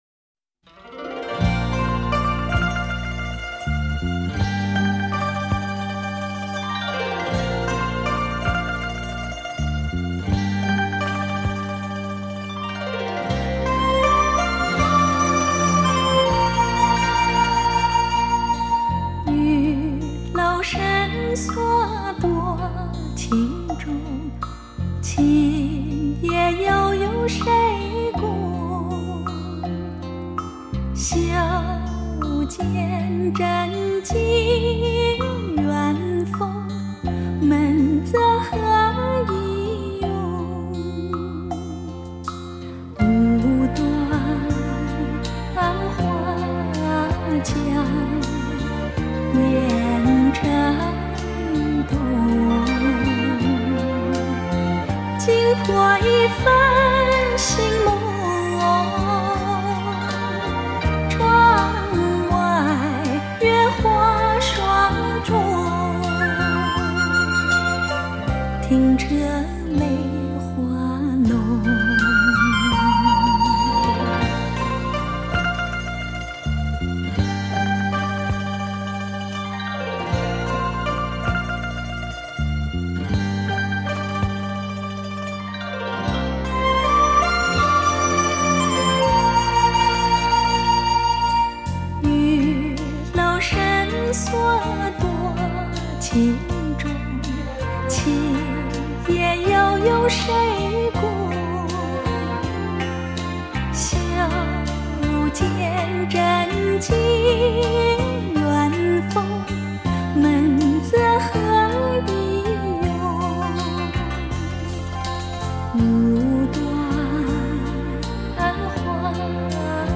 多年来，这张唱片一直被发烧友们作为完美人声的试音碟。